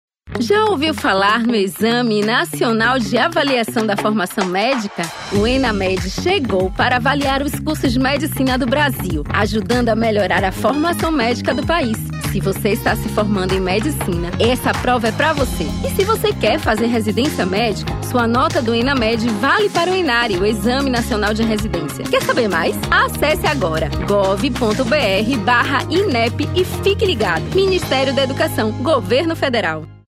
Spots e Jingles produzidos pela rede gov e por órgãos do governo federal.